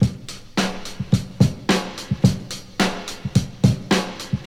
108 Bpm Drum Loop Sample B Key.wav
Free drum loop sample - kick tuned to the B note.
108-bpm-drum-loop-sample-b-key-dNm.ogg